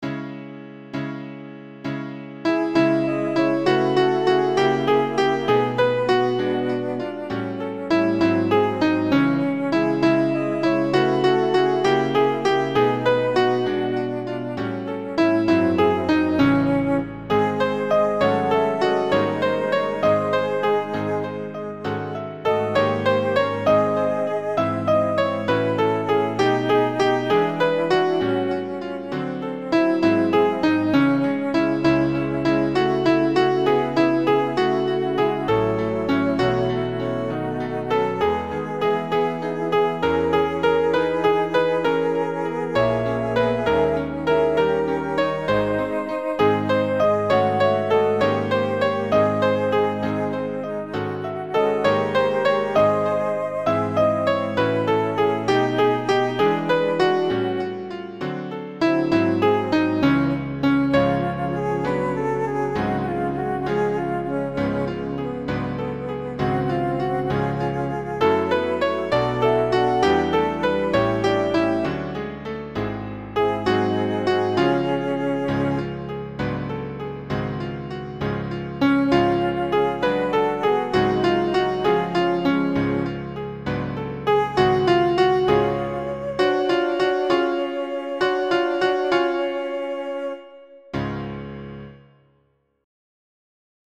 soprane 2
Au-bord-de-leau-Faure-soprane-2.mp3